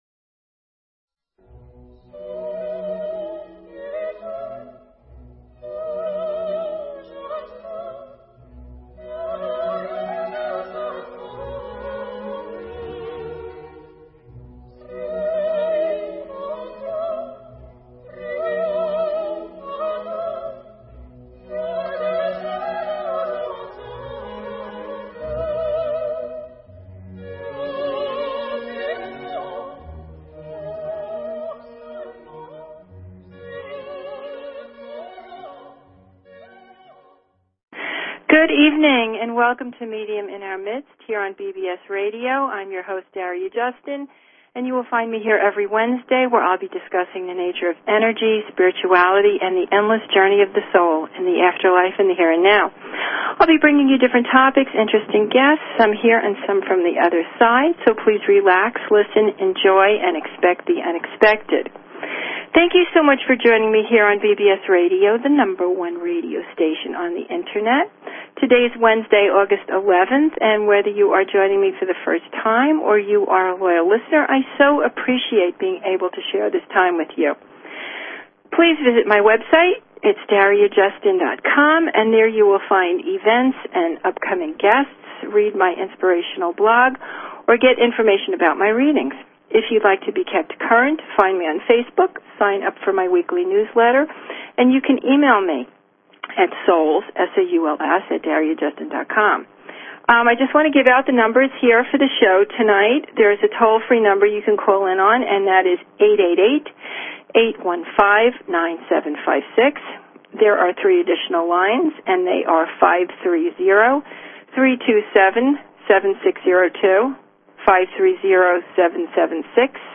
Talk Show Episode, Audio Podcast, Medium_in_our_Midst and Courtesy of BBS Radio on , show guests , about , categorized as